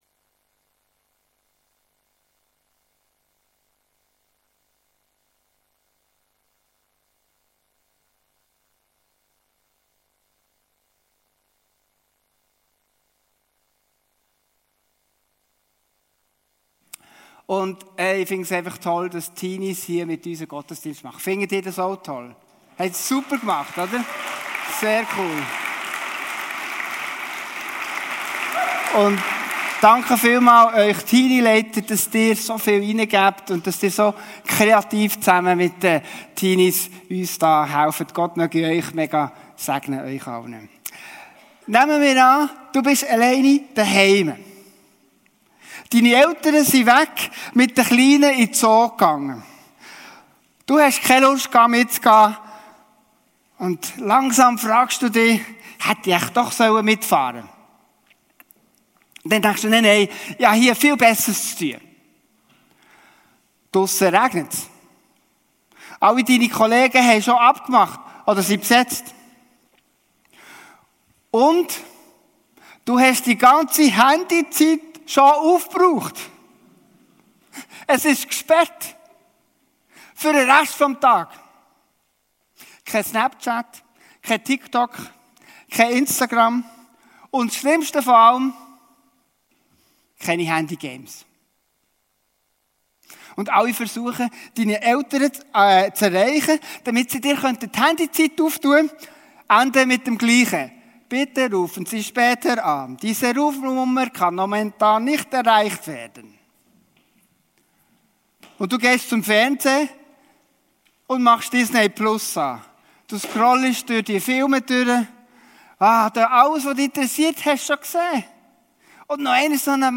Erntedank-Familiengottesdienst ~ Podcast FEG Langenthal Podcast